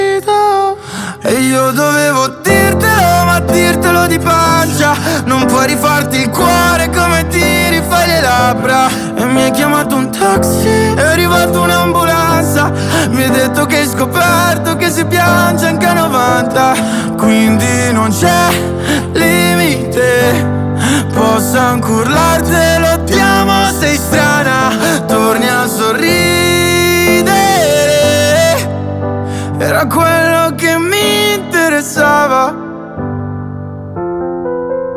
2025-05-08 Жанр: Поп музыка Длительность